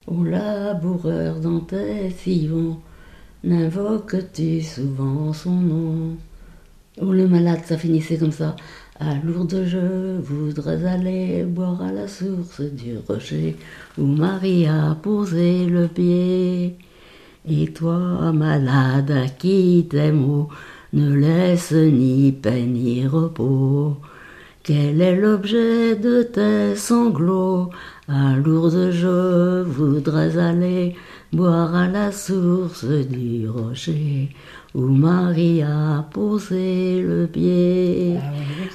cantique
Genre strophique
Pièce musicale inédite